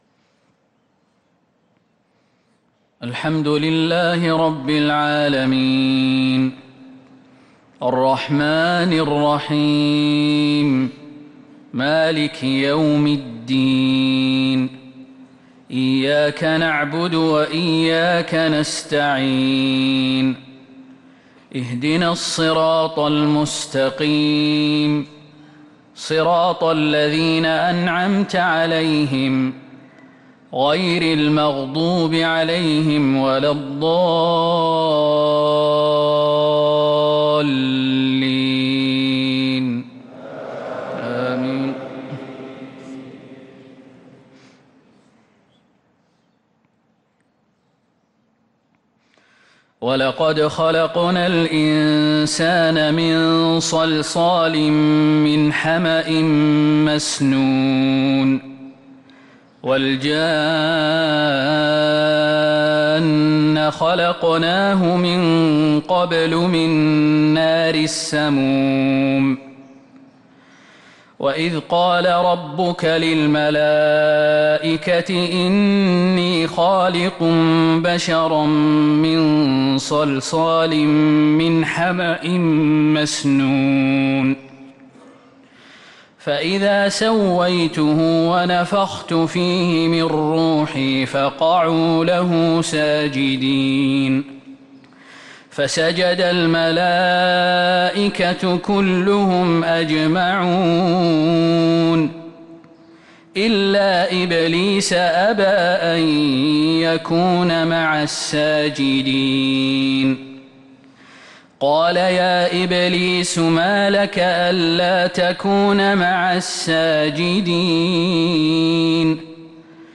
صلاة الفجر للقارئ خالد المهنا 25 محرم 1445 هـ
تِلَاوَات الْحَرَمَيْن .